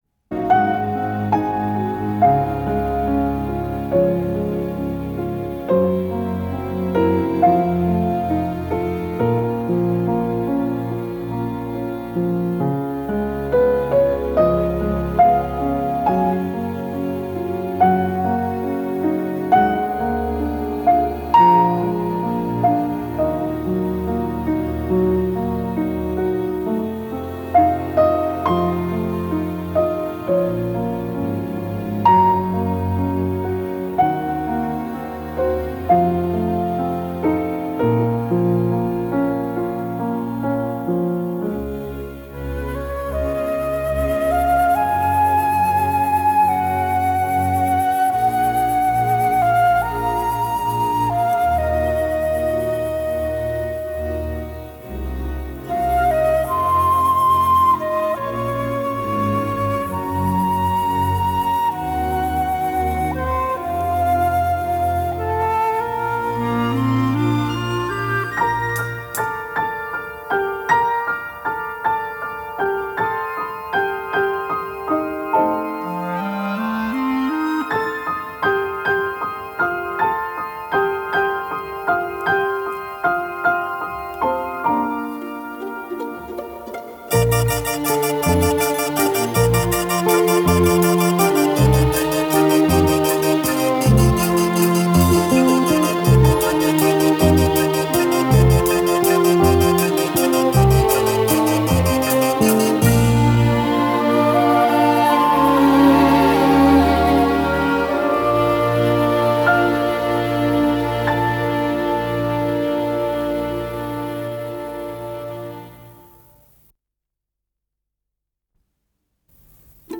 с профессиональной магнитной ленты
2. Andantino (с мал. напряж.) pizz с клависином, ф-но
3. Интермеццо (лирич. осн. тема) solo ф-но
4. Интермеццо (медл. лирич.) Solo ф-но, конец срезан
Скорость ленты38 см/с